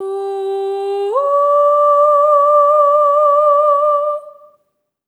SOP5TH G4 -R.wav